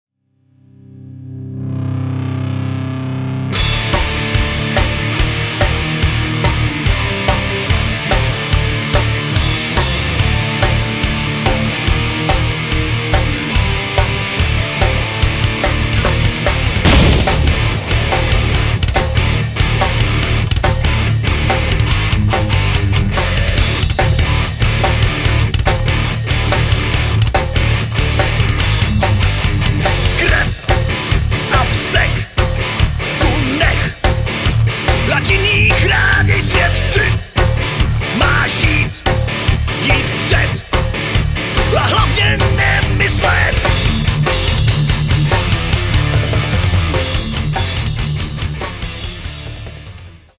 zpěv
kytara
bicí